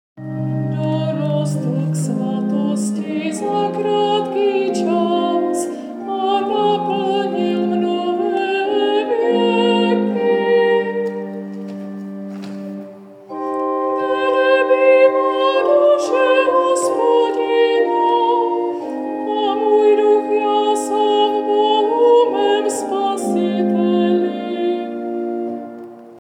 Nápěv antifony ke Kantiku Panny Marie a Kantika
Antifona-k-magnificat-magnificat_cut_26sec.mp3